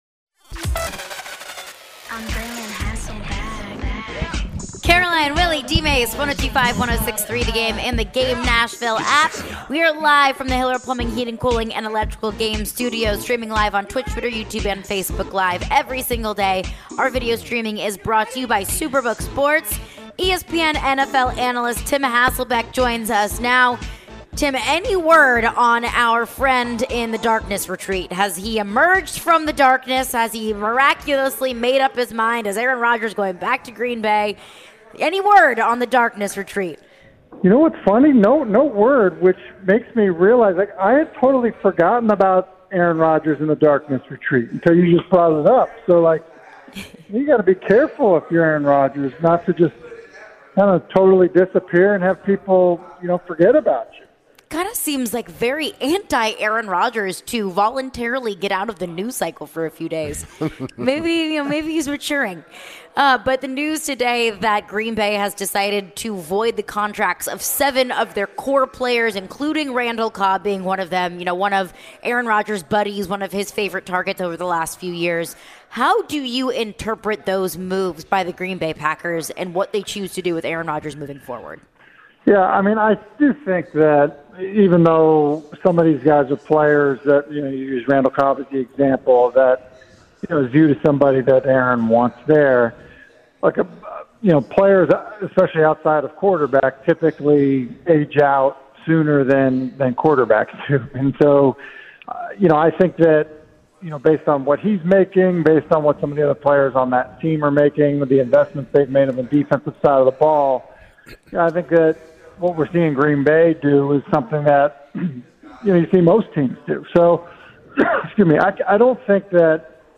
Tim Hasselbeck Interview (2-21-23)